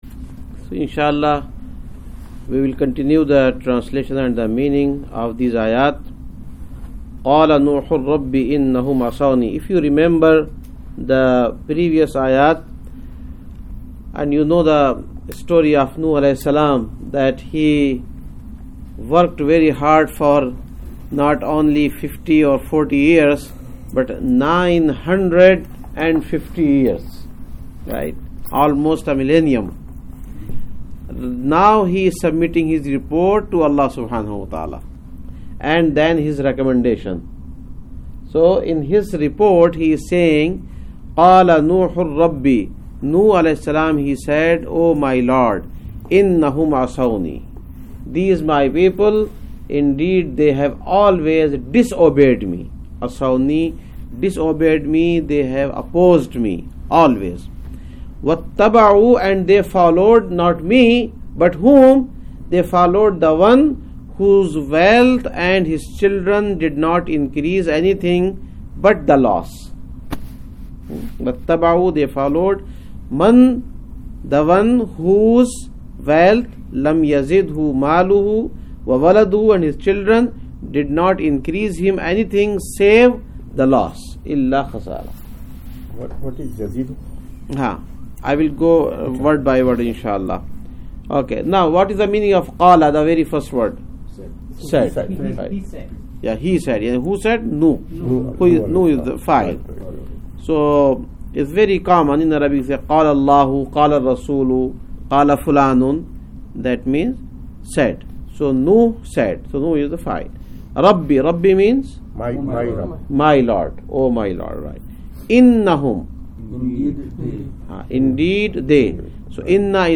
#20 Quran Class - Surah Nooh Ayyat 21-25
#20 Quran Class - Surah Nooh Ayyat 21-25 Adult Quran Class conducted on 2014-08-10 at Frisco Masjid.